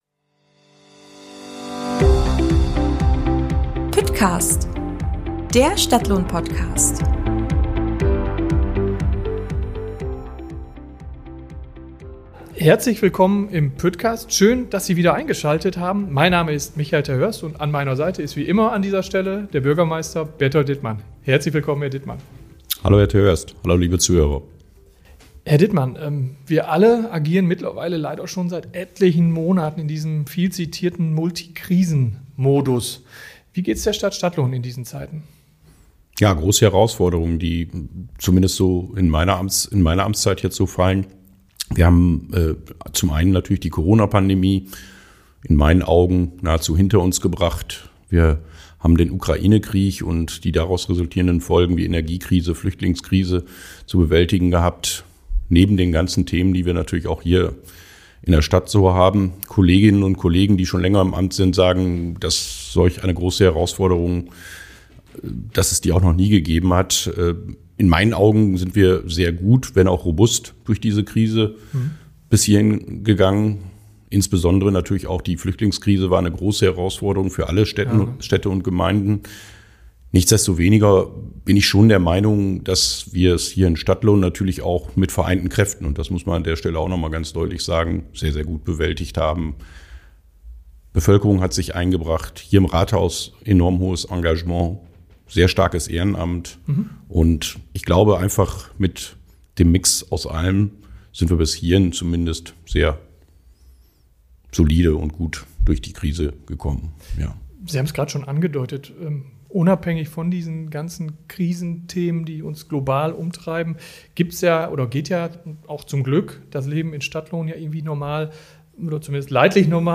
Antworten auf diese Fragen gibt es im ersten Teil des Püttcast-Interviews Der zweite Teil steht dann ganz im Zeichen des Fachbereichs Zentrale Steuerung.